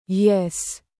Yesssss Efecto de Sonido Descargar
Yesssss Botón de Sonido